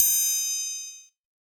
BWB PROMIXED 2 PERC (36).wav